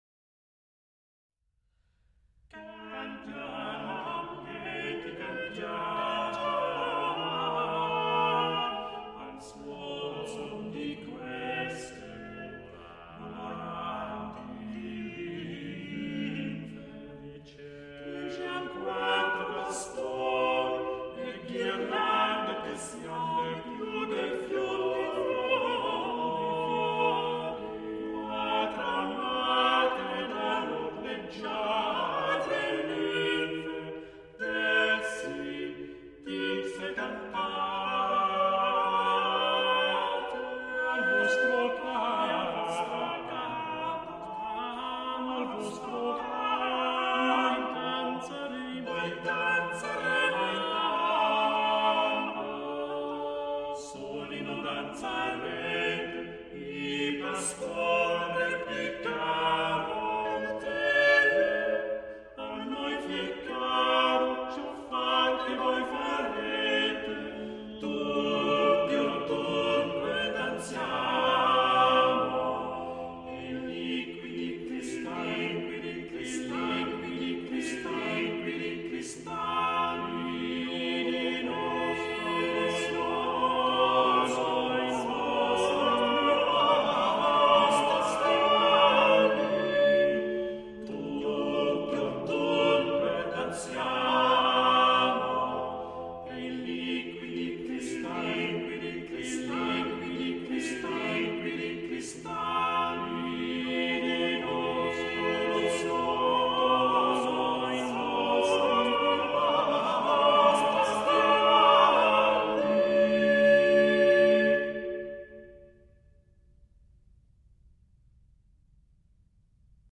Madrigal
Group: A capella